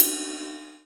071 - Ride-4.wav